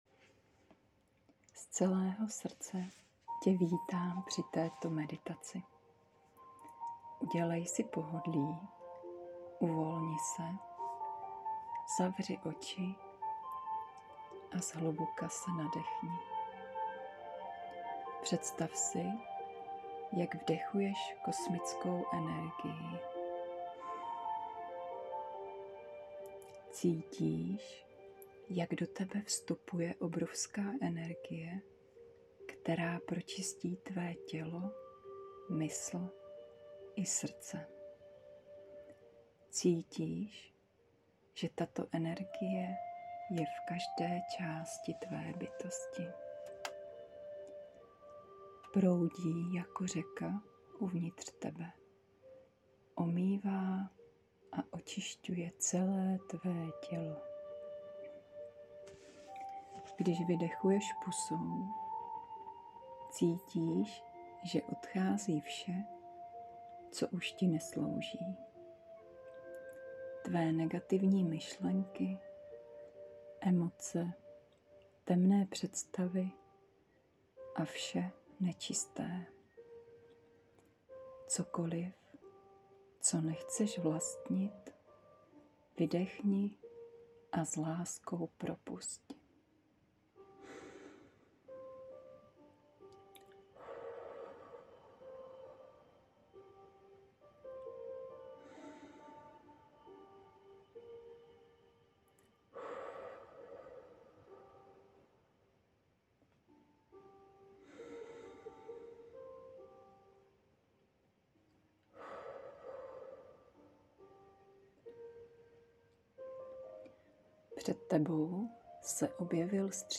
Transformační meditace